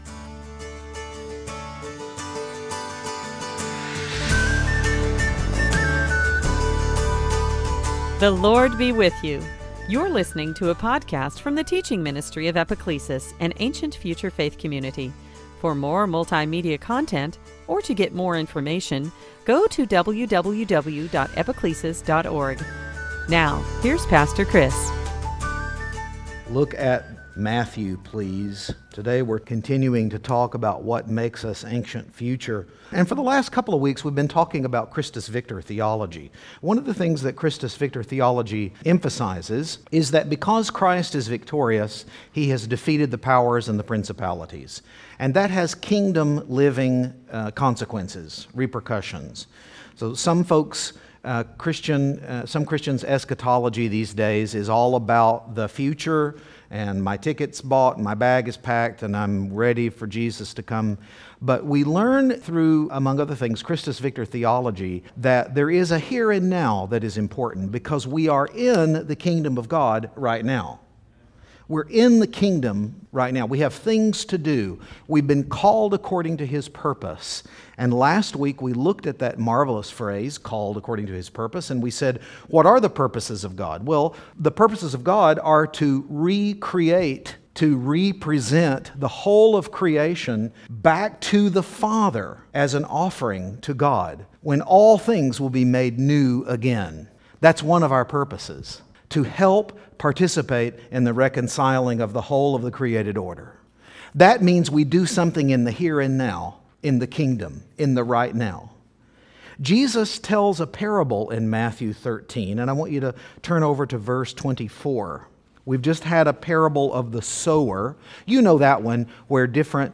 Sunday Teaching